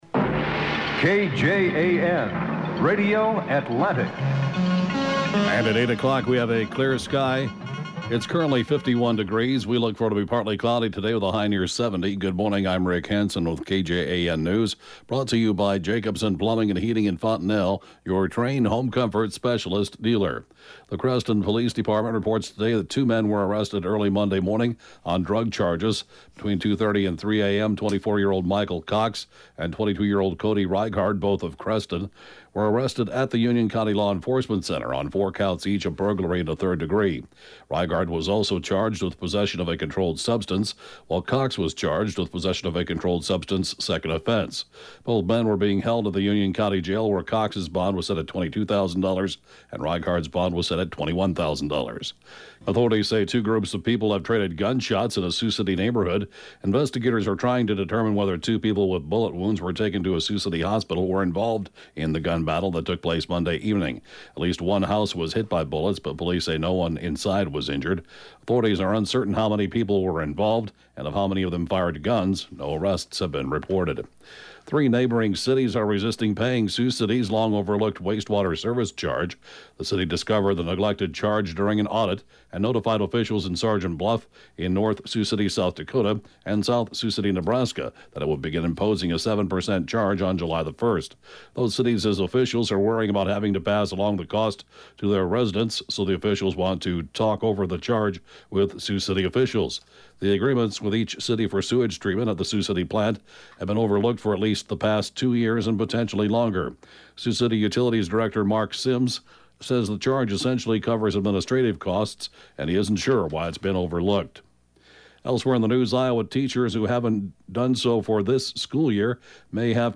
(Podcast) KJAN 8-a.m. News, 9/5/2017